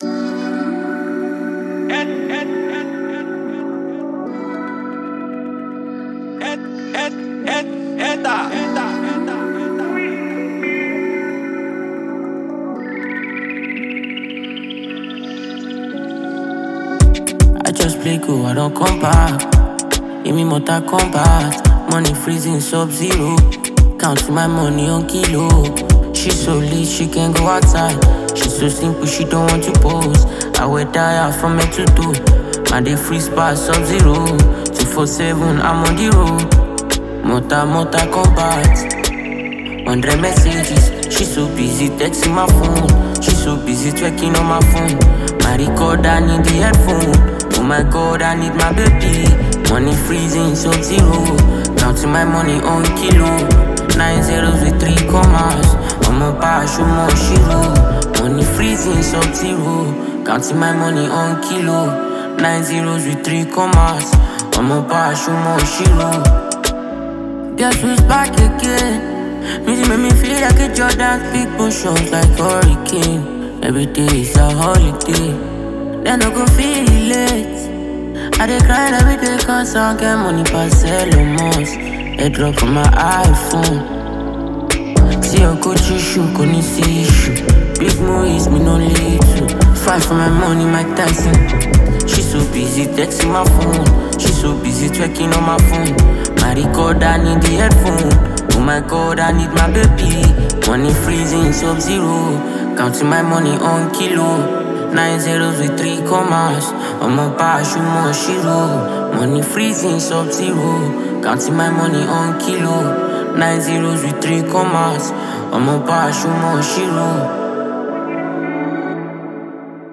Energetic New Single
Fusing road power with melodic aptitude
boundary-pushing Afrobeats